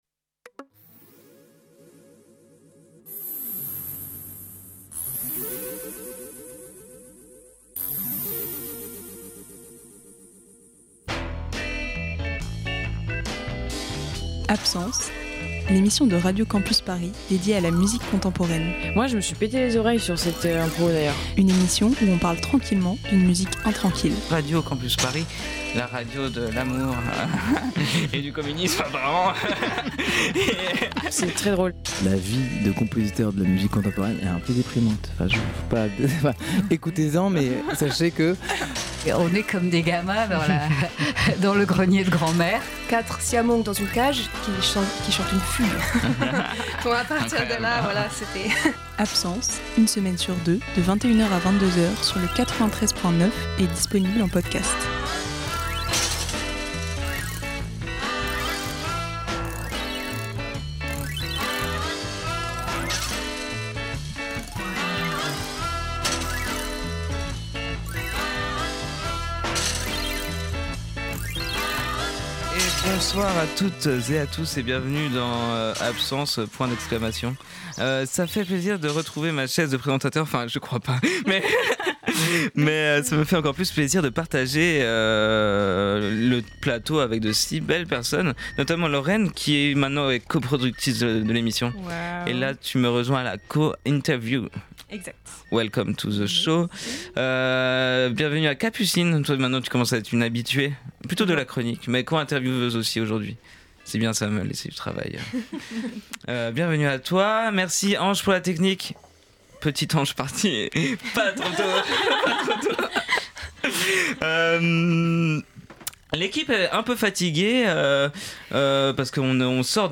Impro impro